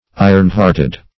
Search Result for " iron-hearted" : The Collaborative International Dictionary of English v.0.48: Iron-hearted \I"ron-heart`ed\, a. Hard-hearted; unfeeling; cruel; as, an iron-hearted master.